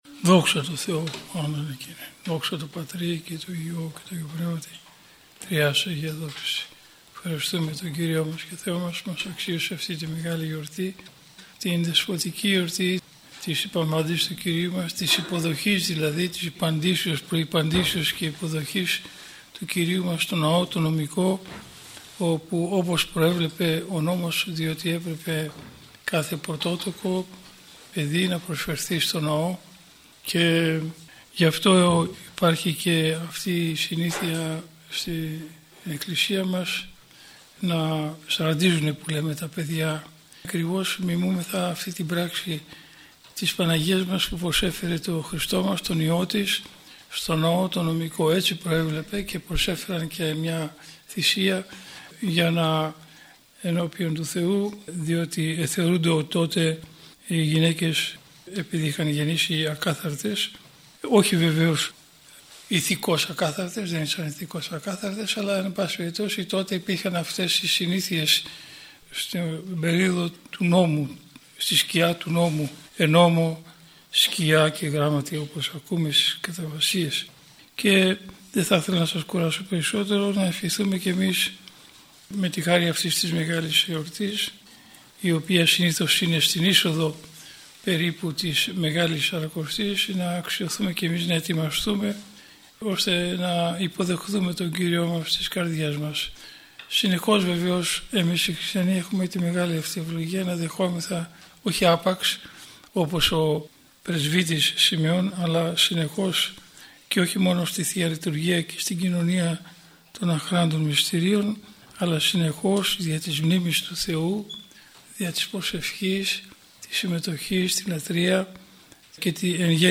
•  Ὁμιλία εἰς τὴν Ἀγρυπνία τῆς Ὑπαπαντῆς 2025